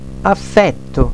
La pronuncia indicata qui è quella standard; va ricordato, però che la pronuncia aperta o chiusa delle e e delle o varia da regione a regione.
é = e chiusa; è e aperta
affètto (l') noun S M affètti (gli) __ affection
affetto_ap.wav